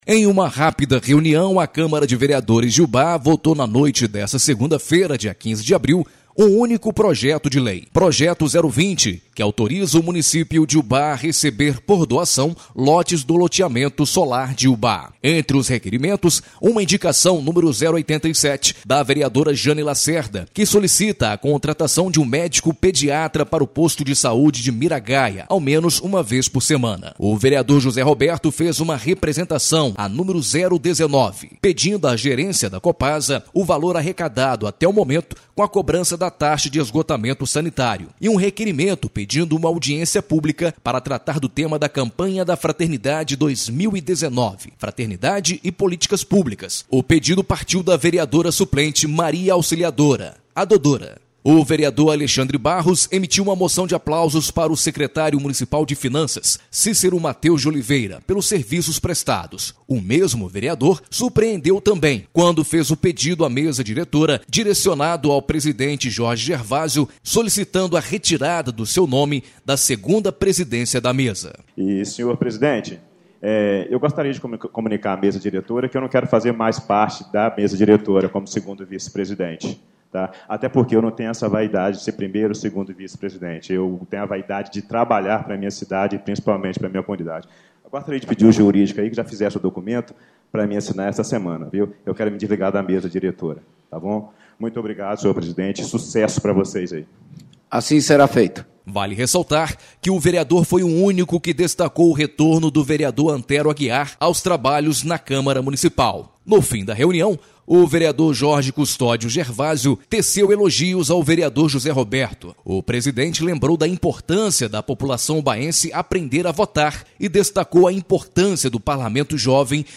Ouça o Informativo Câmara exibido na Rádio Educadora AM/FM Ubá – MG